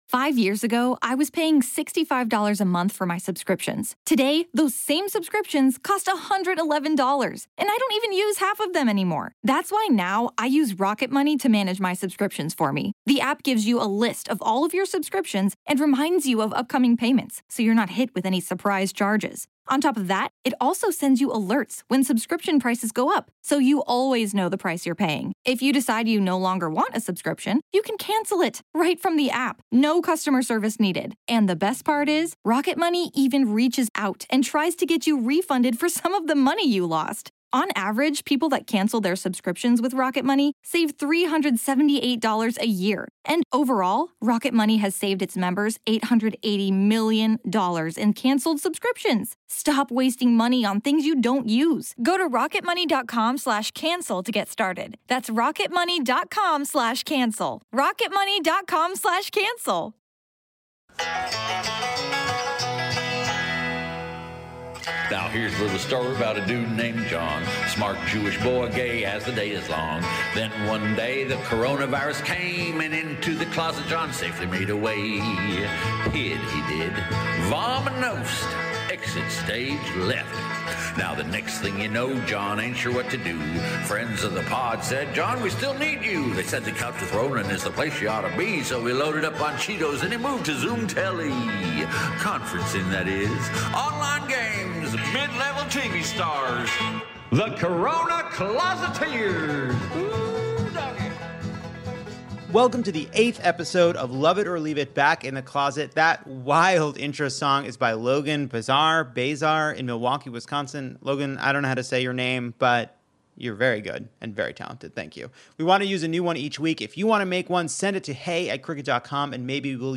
And we try out jokes on unsuspecting strangers which was very exciting and went GREAT. We're still in the closet and we're making the best of it.